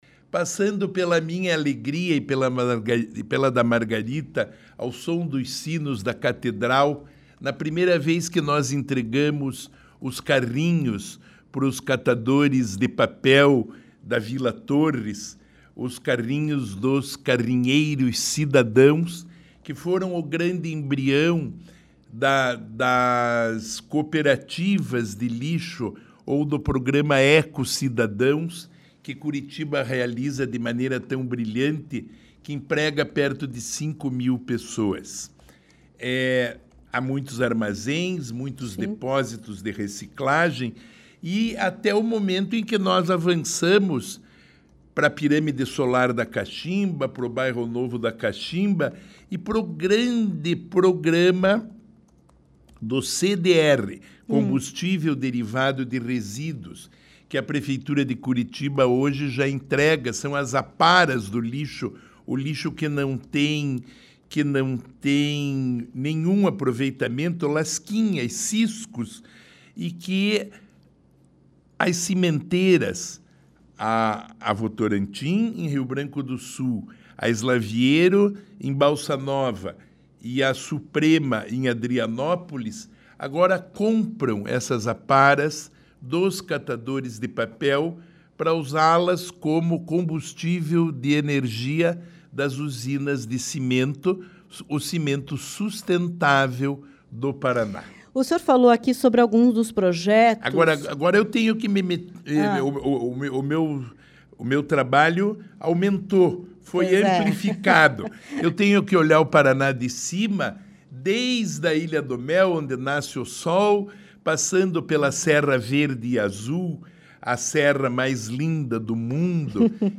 O secretário de Estado do Desenvolvimento Sustentável, Rafael Greca, esteve na CBN Curitiba nesta terça-feira (22) para comentar os próximos projetos da pasta em todo o Paraná.